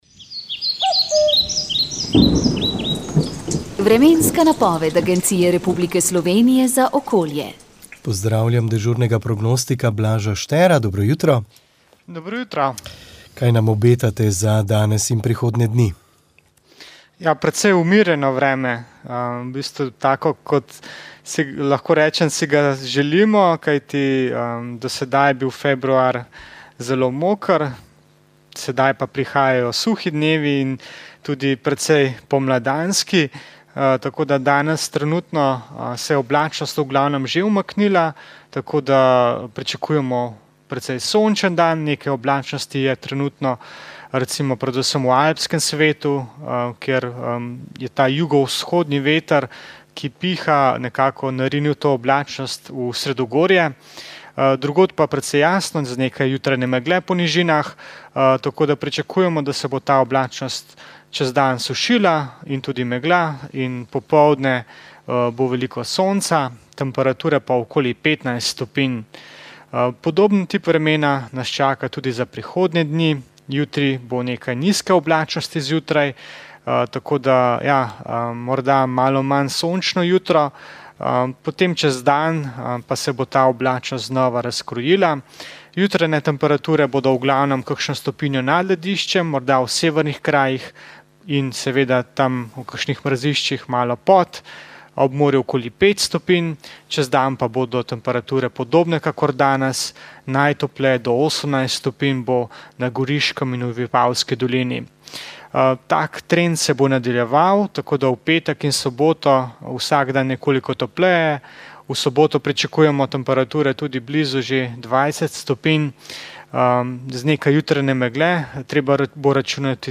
Molitev je vodil nadškof Alojz Uran.